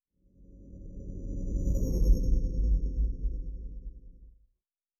Sci-Fi Sounds / Movement / Fly By 06_3.wav
Fly By 06_3.wav